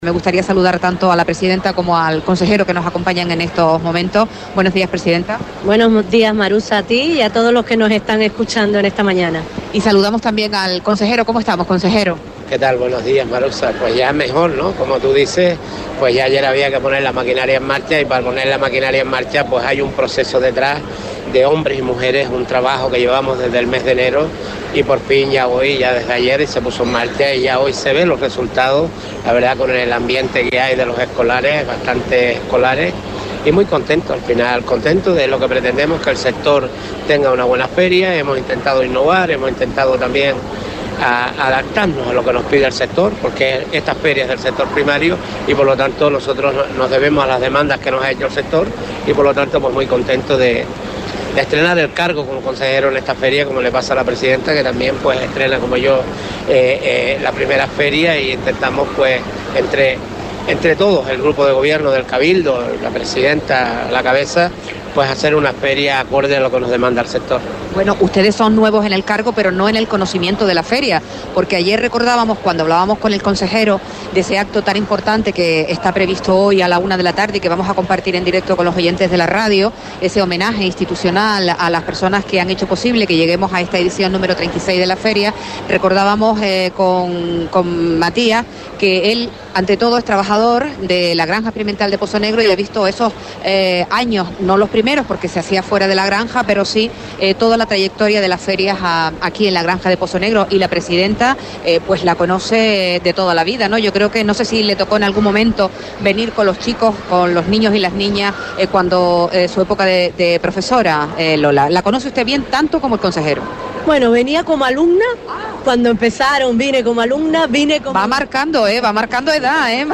Comenzamos en directo la jornada de viernes en Feaga junto a Lola García, presidenta del Cabildo de Fuerteventura, y Matías Peña, consejero del Sector Primario.
Entrevistas